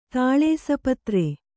♪ tāḷēsa patre